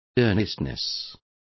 Complete with pronunciation of the translation of earnestness.